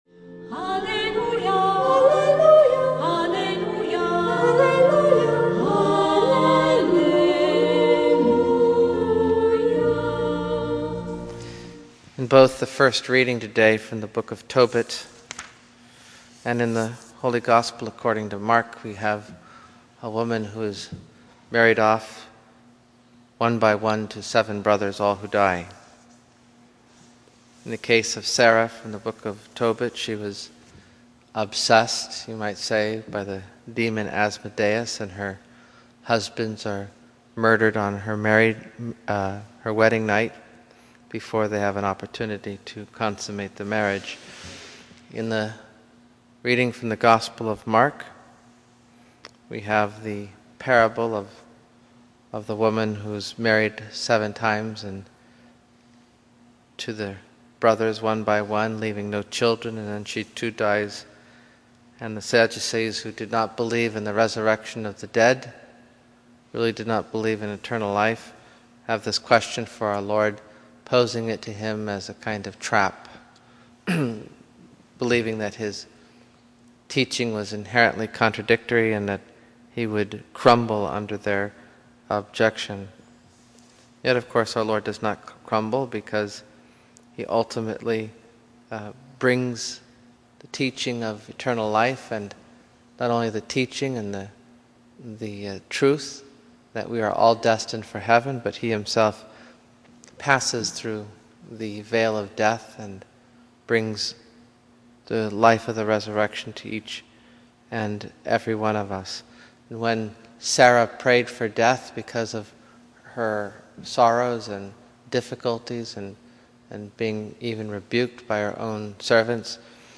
Homilies #89 - Wednesday Homily (6min) >>> Play Ave Maria! We have many tears to shed before Judgment and Resurrection, and we glimpse some of those tears in today's readings, first in reading the story of Tobit and Sarah, and then in listening to the hard hearts of the Sadducees.